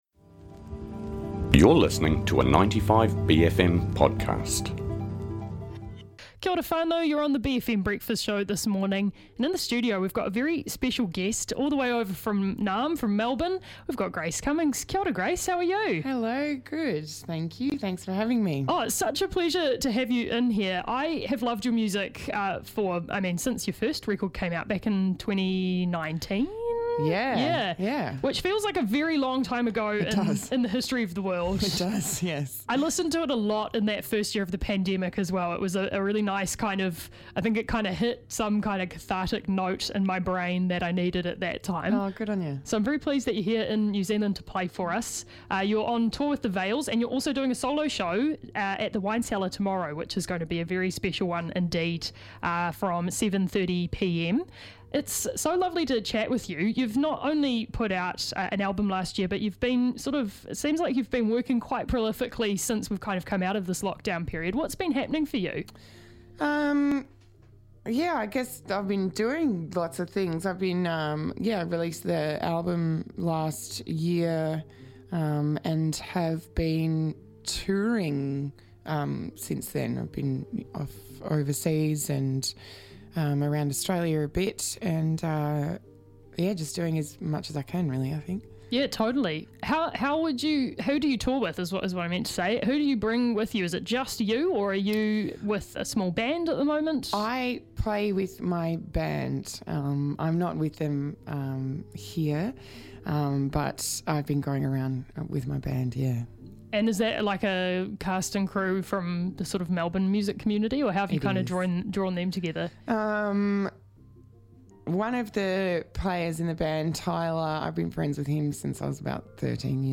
is in the studio for some beautiful live acoustic tracks